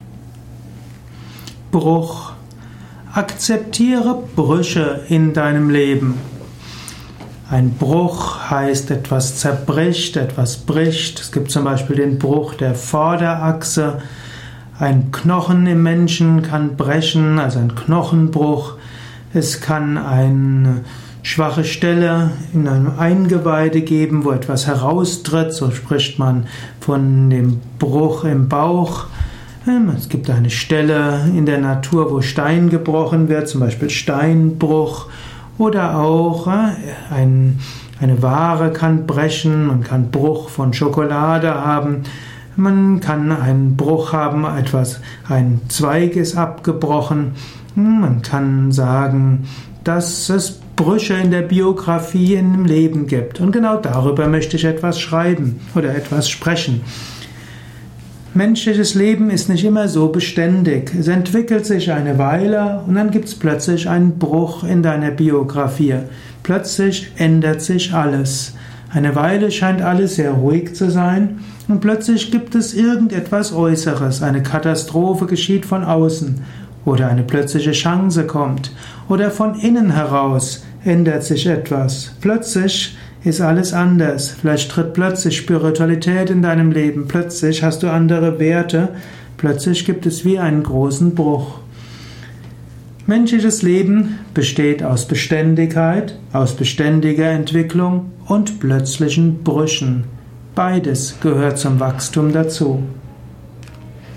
Ein kurzer Vortrag mit Inhalt Bruch. Erfahre einiges zum Thema Bruch in diesem Kurzaudio.